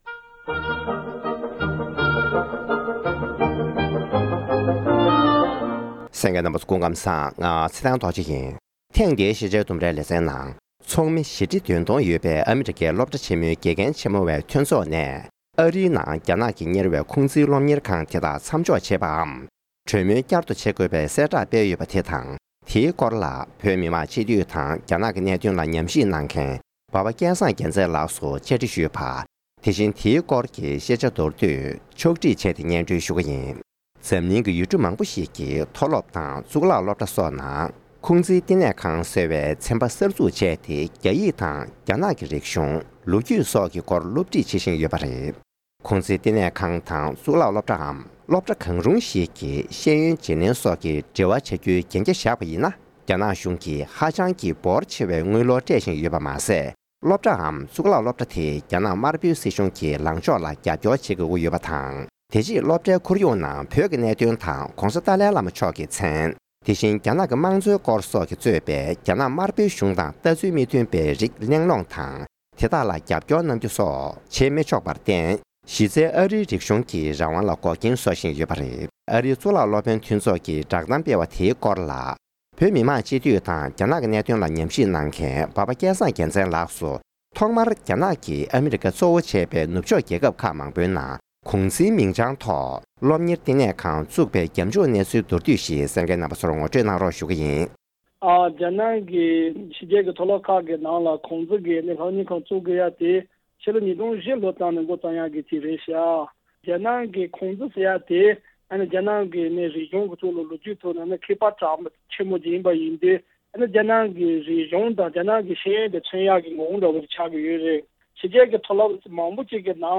༄༅། །ཐེངས་འདིའི་ཤེས་བྱའི་ལྡུམ་རྭ་ཞེས་པའི་ལེ་ཚན་འདིའི་ནང་། རྒྱ་ནག་གི་གནད་དོན་ལ་ཉམས་ཞིབ་གནང་མཁན་བོད་མི་མང་སྤྱི་འཐུས་འབའ་པ་སྐལ་བཟང་རྒྱལ་མཚན་ལགས་སུ་ཉེ་ཆར་ཨ་རིའི་གཙུག་ལག་སློབ་དཔོན་མཐུན་ཚོགས་དེས་རྒྱ་ནག་གི་ཁུང་ཙི་བལྟི་གནས་ཁང་དང་འབྲེལ་བ་མཚམས་འཇོག་བྱ་རྒྱུ་སོགས་ཀྱི་སྐོར་ལ་བསྒྲགས་གཏམ་ཞིག་སྤེལ་ཡོད་པ་དེའི་ཐད་གནས་འདྲི་ཞུས་པ་ཞིག་གསན་རོགས་གནང་།།